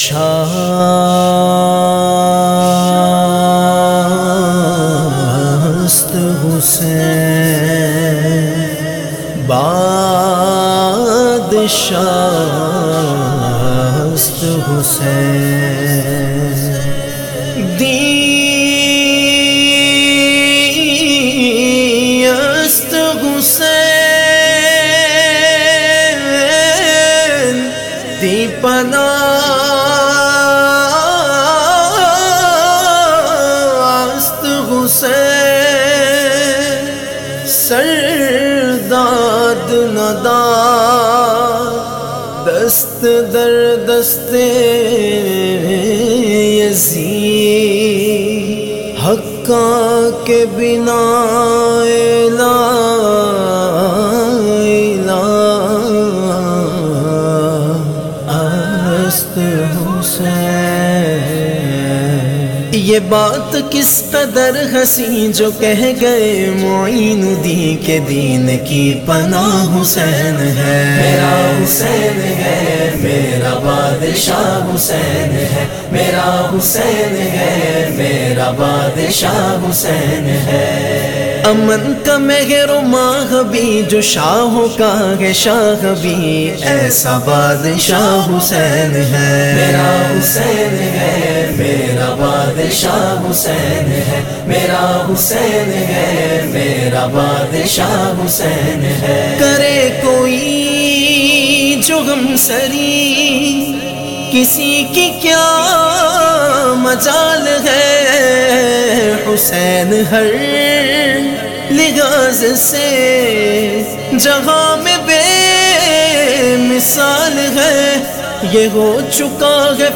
This Manqabat Related to Hazrat-e-Imam-e-Hussain